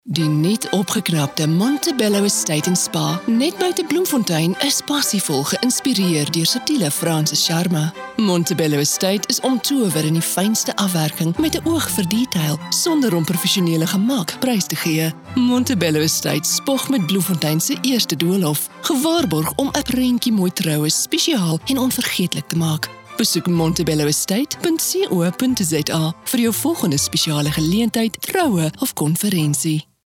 Female
Approachable, Authoritative, Confident, Conversational, Corporate, Gravitas, Natural, Reassuring, Warm
South African
My voice has been described as warm, earthy, sensual, clear and authoritative.
Afrikaans PSA Sanofi Pasteur.mp3